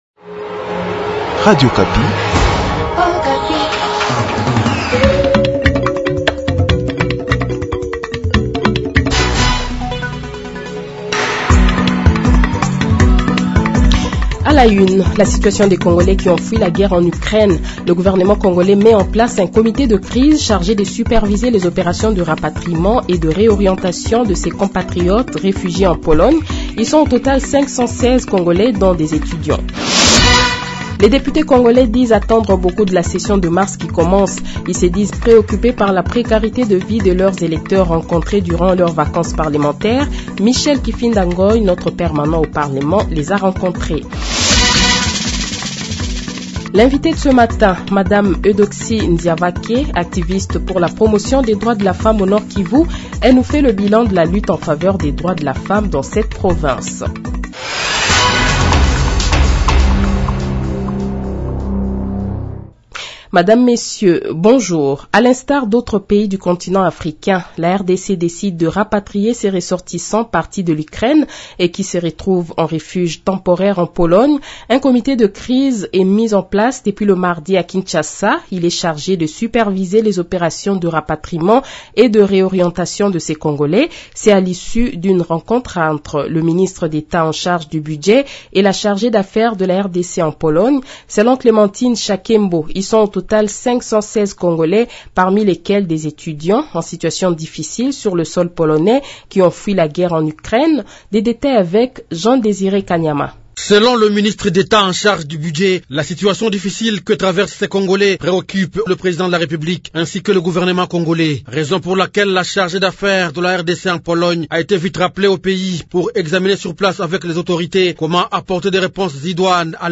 Le Journal de 7h, 10 Mars 2022 :